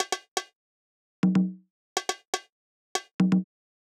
AIR Beat - Congas.wav